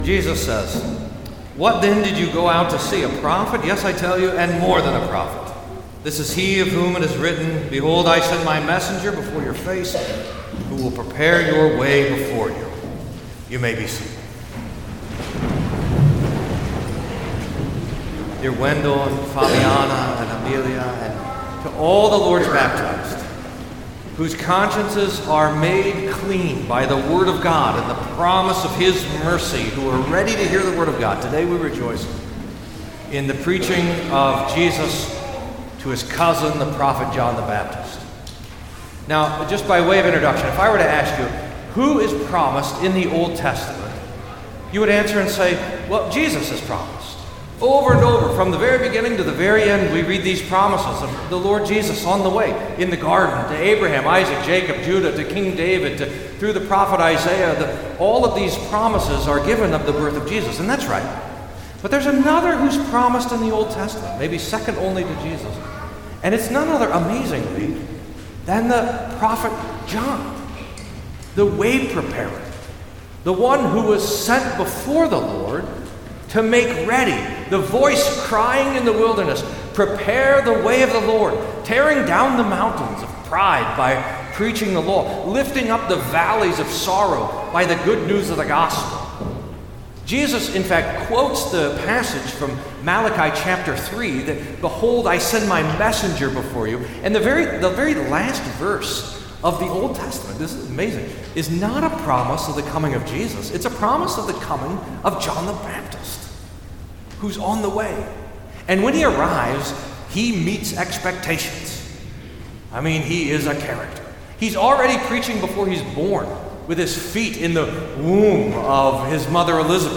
Sermons Archive - St Paul Lutheran Church podcast